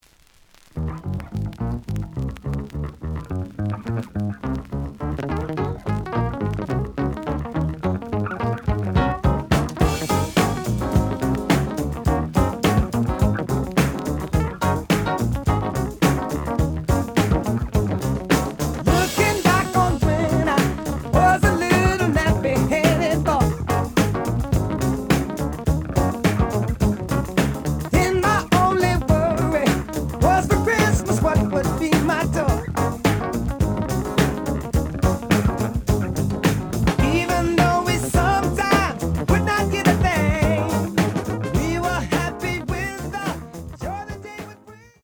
The audio sample is recorded from the actual item.
●Format: 7 inch
●Genre: Funk, 70's Funk
Looks good, but slight noise on both sides.)